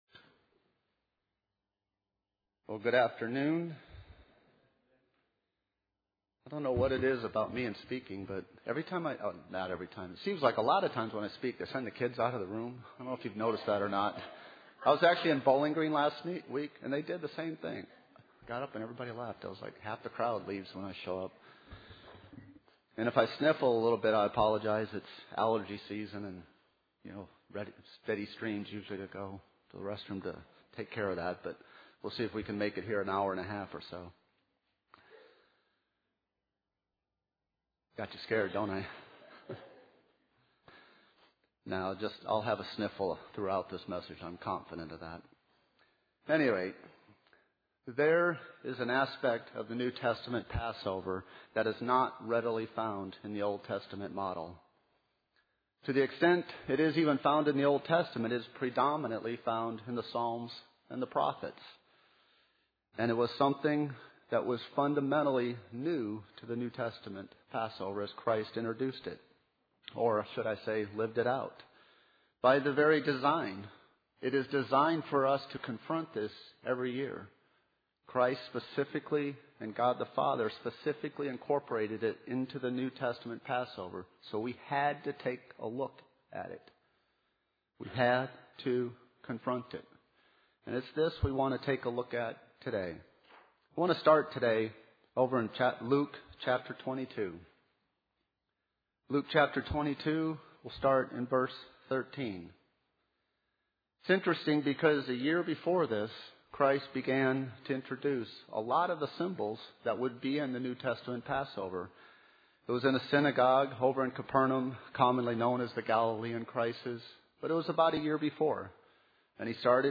Given in Nashville, TN
Read our FREE booklet: Jesus Christ: The Real Story UCG Sermon Studying the bible?